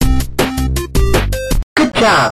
good_job_penny.ogg